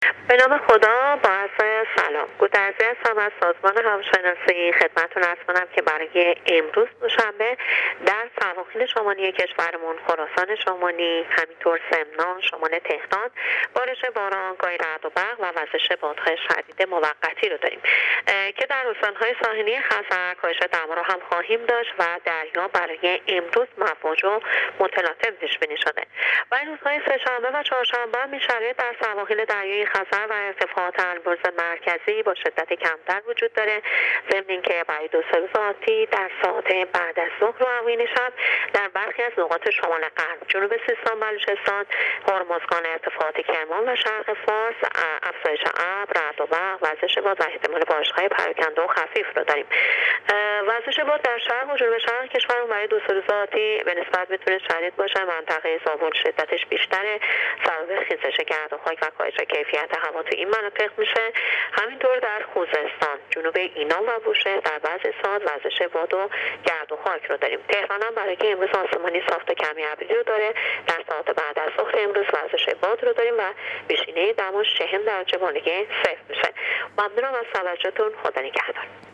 دریافت فایل weather با حجم 1 MB برچسب‌ها: هواشناسی - گزارش رادیو تلویزیون